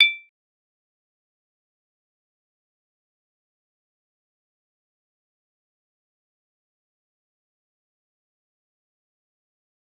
G_Kalimba-D8-mf.wav